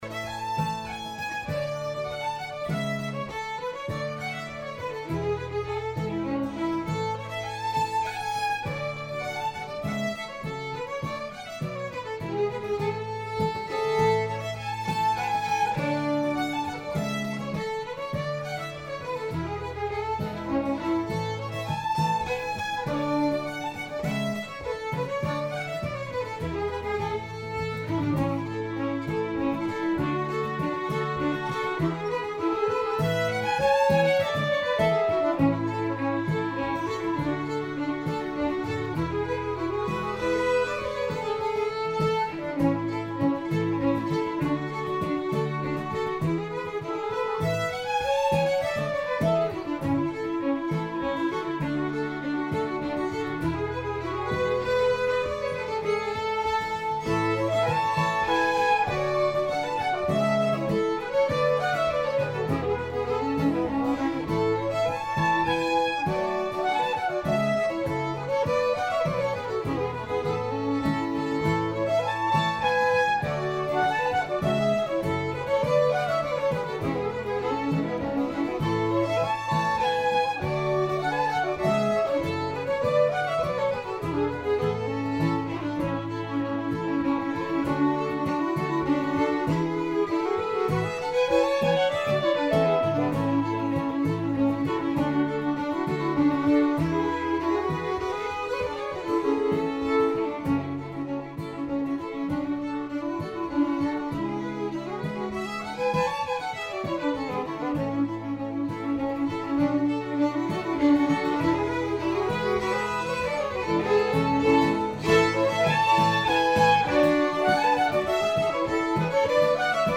Låttyp: polska.